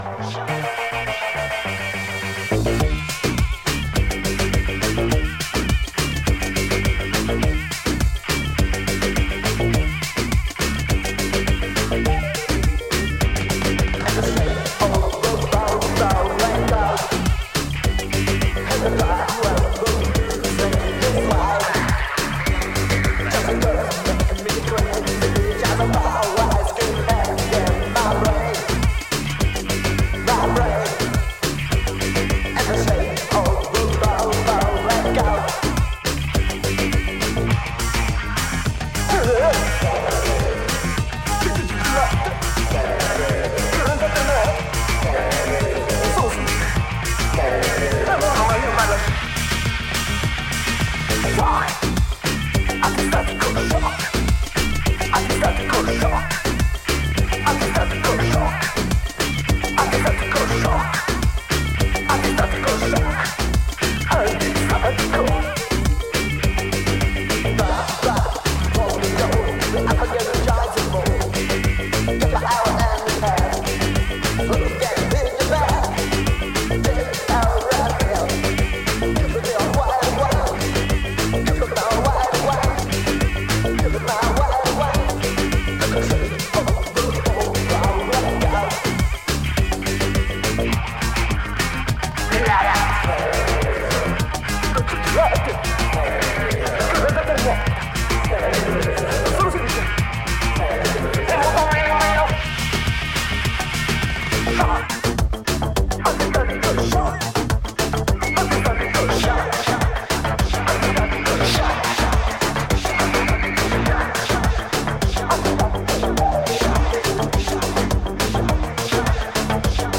シンセ・ディスコ/エレクトロ度が格段に上昇、よくぞここまで違和感無く手を加えられたな、という仕上がり。